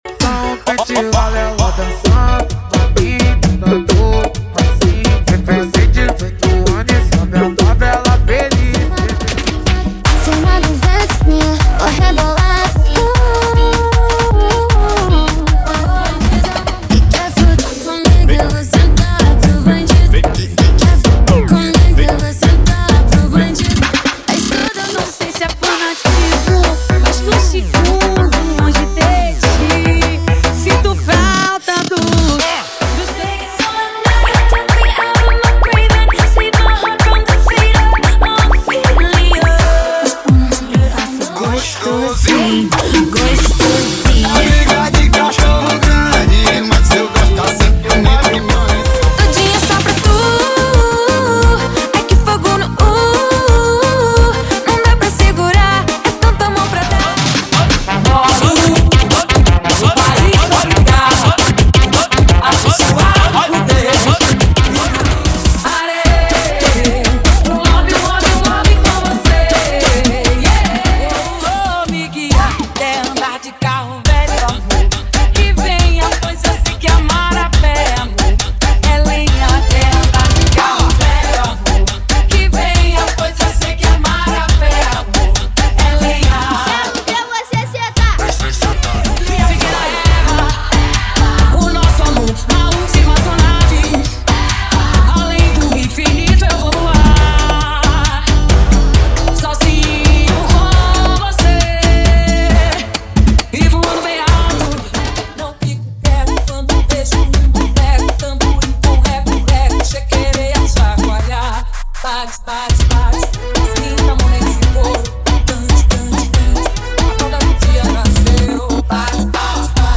• Funk Light e Funk Remix = 100 Músicas
• Sem Vinhetas
• Em Alta Qualidade